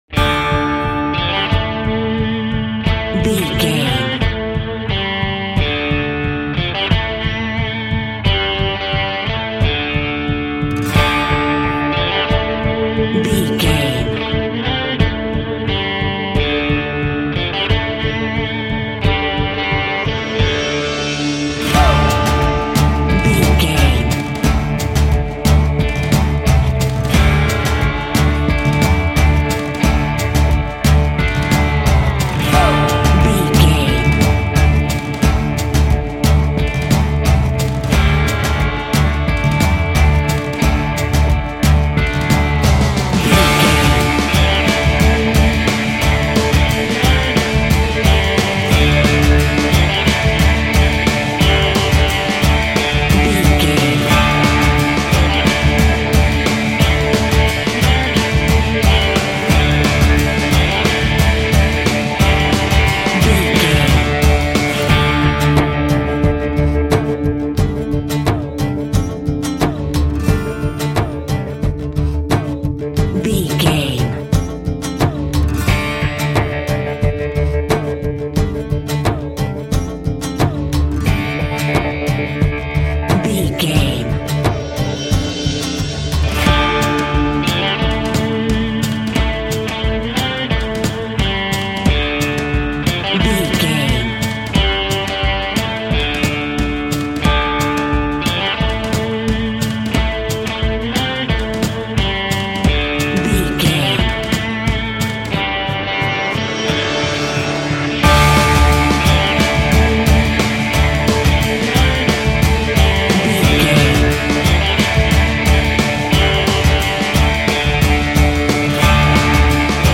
Epic / Action
Aeolian/Minor
aggressive
epic
foreboding
driving
energetic